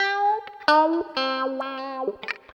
136 GTR 1 -R.wav